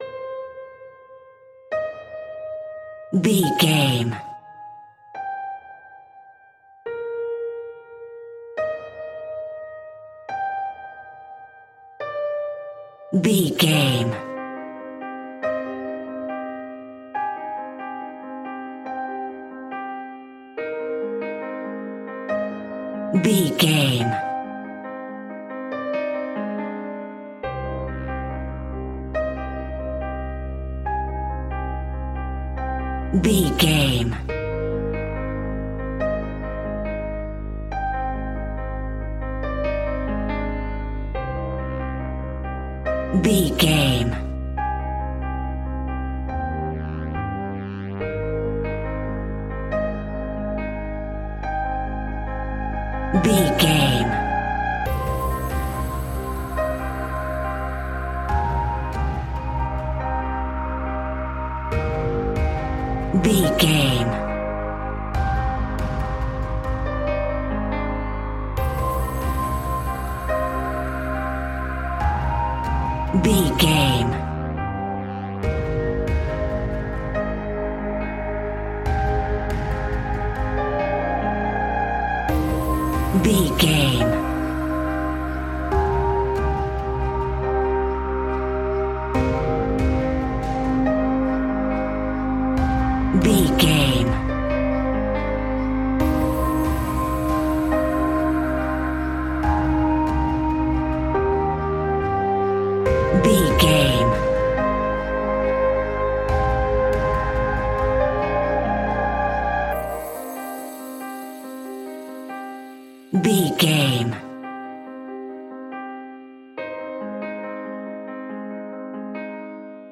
In-crescendo
Thriller
Aeolian/Minor
ominous
haunting
eerie
piano
strings
synthesiser
percussion
brass
horror music